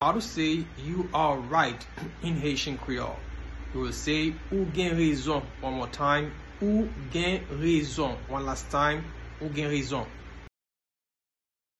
Listen to and watch “Ou gen rezon” pronunciation in Haitian Creole by a native Haitian  in the video below:
You-are-right-in-Haitian-Creole-Ou-gen-rezon-pronunciation-by-a-Haitian-teacher-1.mp3